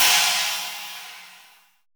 METAL CHINA.wav